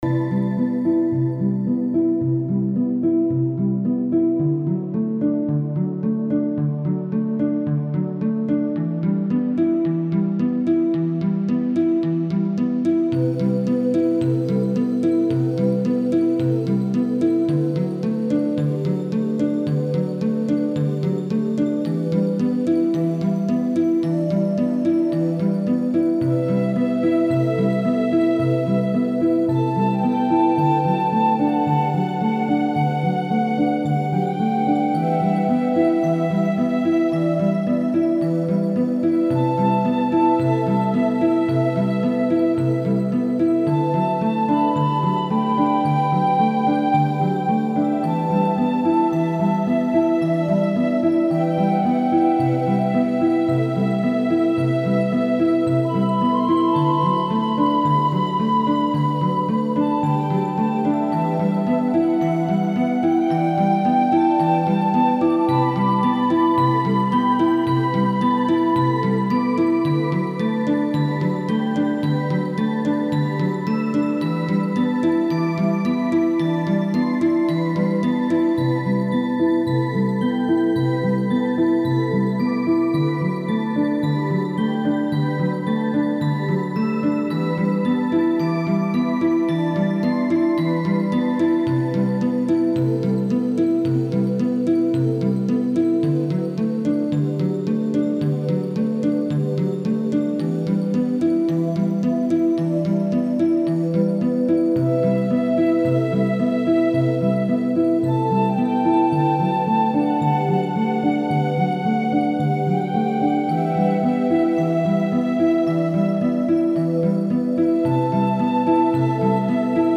Music: Underwater.Looped: Yes.Format: MP3, WAV.--Bell, flute and arpeggios. Suitable for underwater, cave level or puzzle game.